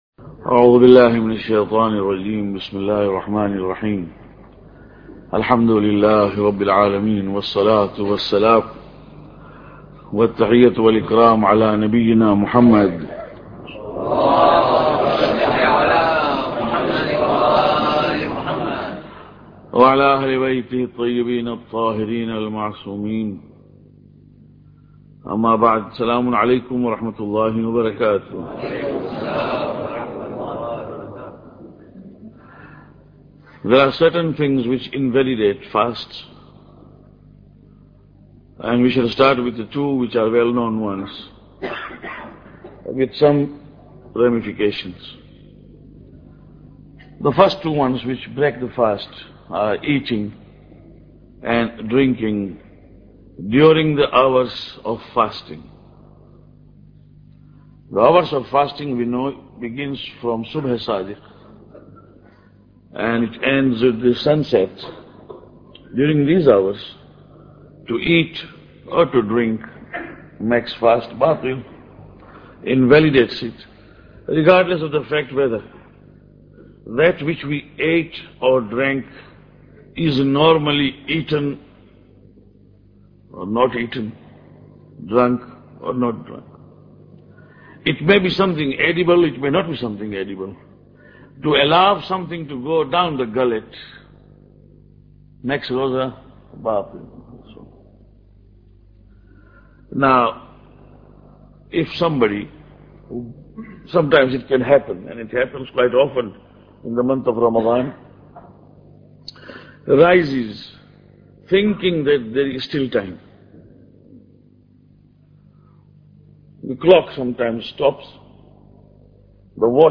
Lecture 8